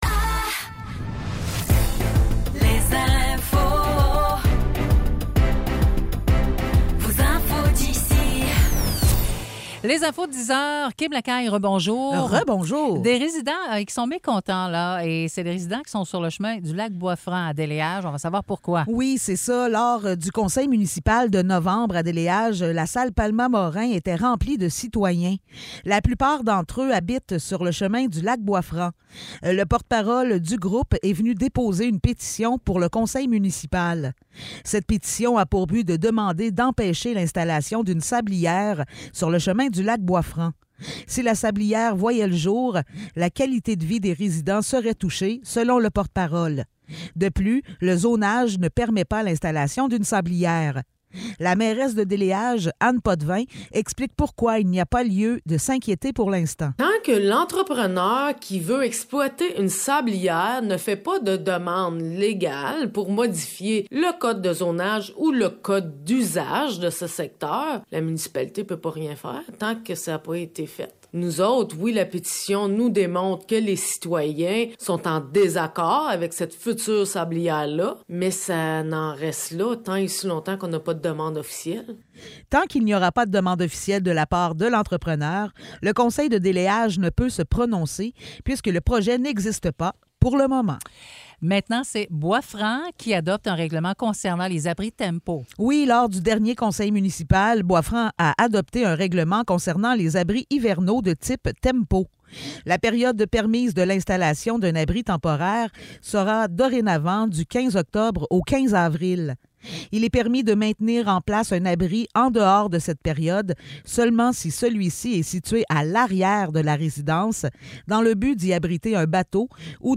Nouvelles locales - 10 novembre 2023 - 10 h